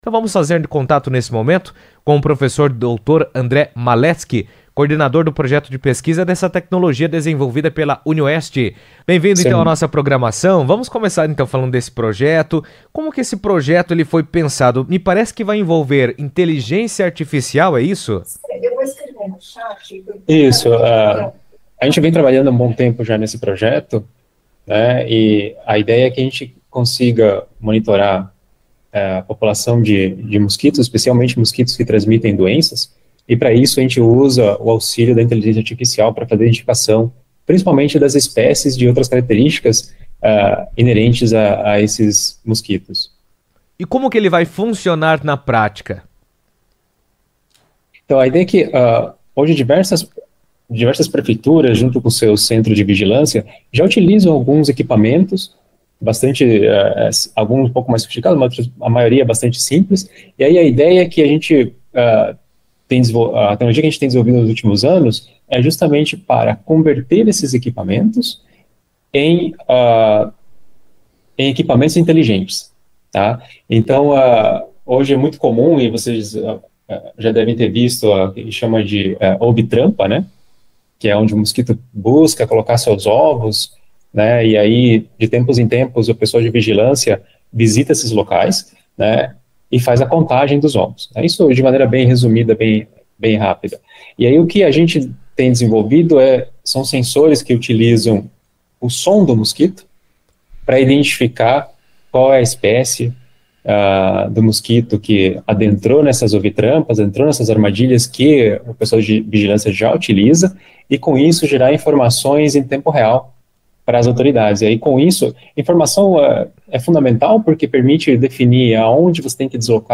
Um projeto inovador desenvolvido pelo Campus da Unioeste em Foz do Iguaçu utiliza inteligência artificial para monitorar o mosquito transmissor da dengue, oferecendo um sistema mais eficiente de vigilância e prevenção. A iniciativa combina tecnologia e ciência aplicada para mapear focos de infestação e apoiar ações de controle. O tema foi abordado na CBN Cascavel